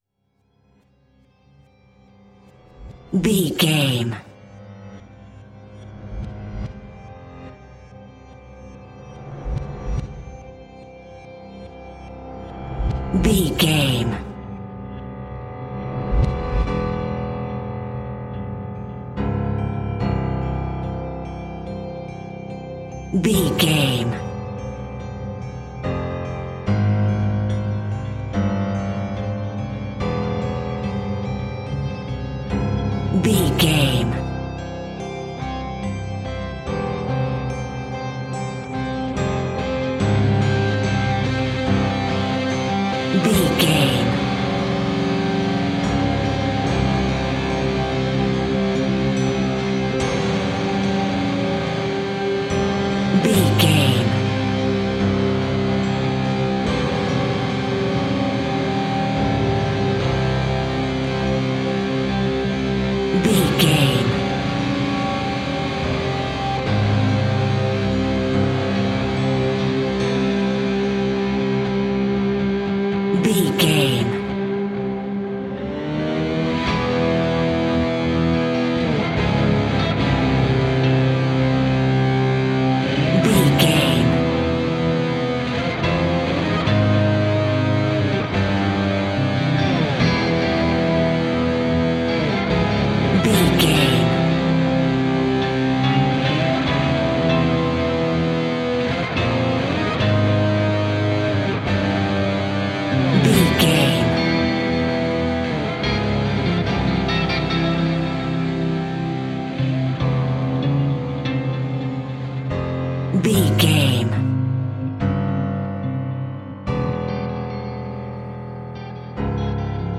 Fast paced
In-crescendo
Ionian/Major
industrial
dark ambient
EBM
synths
Krautrock